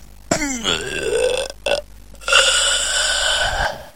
描述：僵尸呻吟声
声道立体声